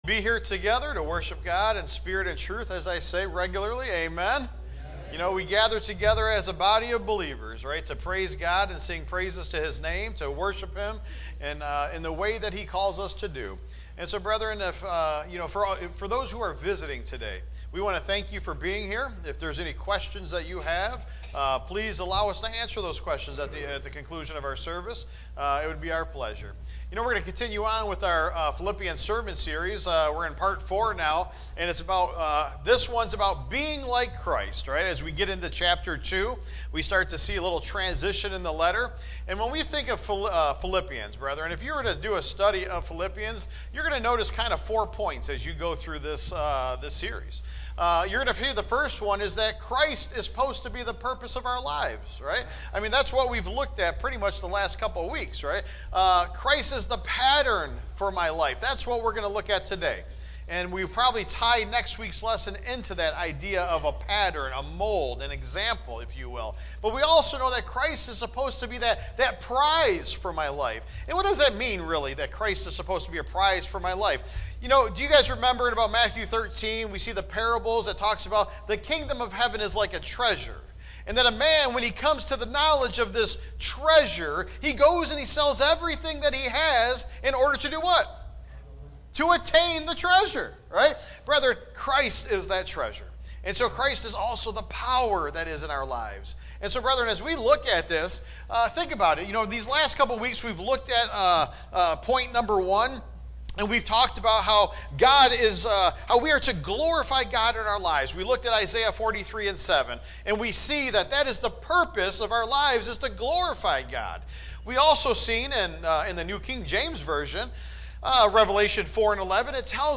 Proverbs Watch Listen Save Taught live Sunday, October 5, 2025 #1 – Christ is the PURPOSE of My Life! #2 – Christ is the PATTERN of My Life! #3 – Christ is the PRIZE of My Life! #4 – Christ is the POWER of My Life!
Tagged with sermon Audio (MP3) 13 MB Previous Seek Godly Wisdom Next Holy Spirit Part 3